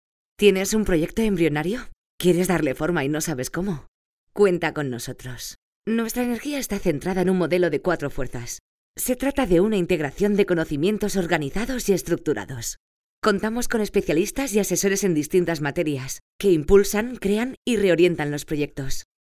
Locutores profesionales para la grabación de podcast.